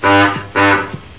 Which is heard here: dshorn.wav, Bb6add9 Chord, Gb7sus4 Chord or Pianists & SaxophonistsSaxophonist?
dshorn.wav